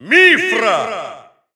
Announcer pronouncing Mythra's name in German.
Mythra_Russian_Announcer_SSBU.wav